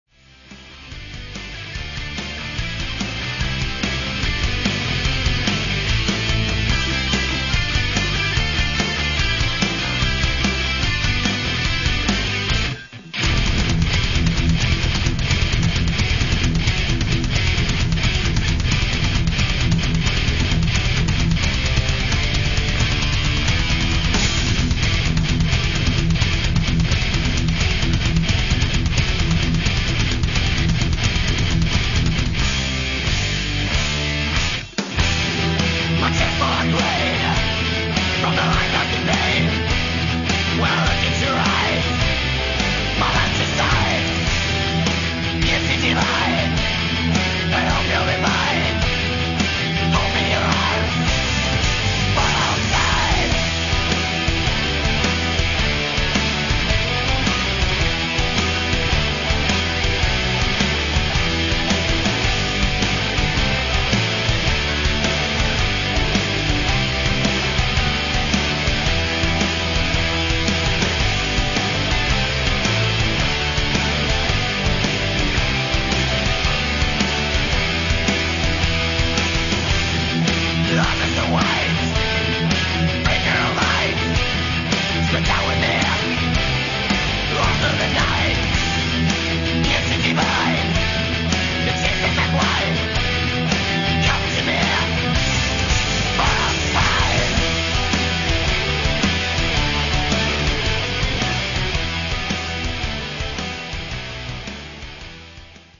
Все файлы размещены с качеством 32 Кбит/с, 22 кГц, моно